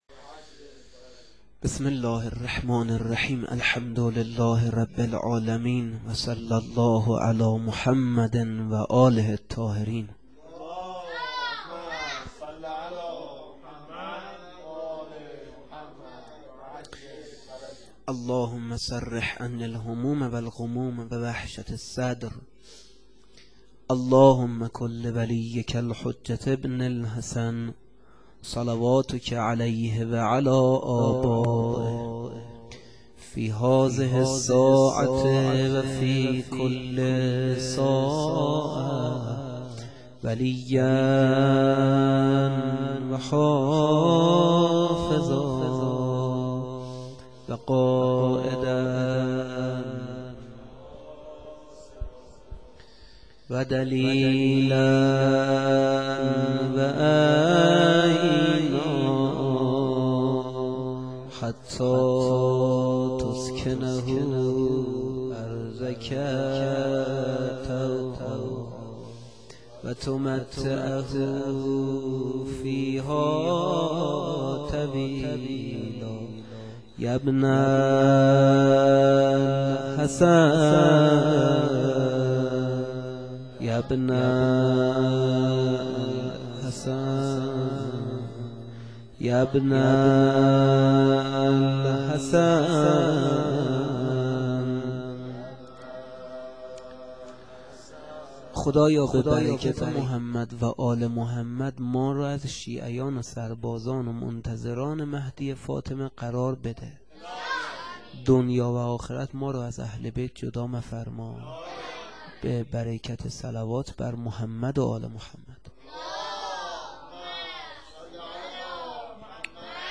سخنرانی - بخش اول
sokhanrani-A.wma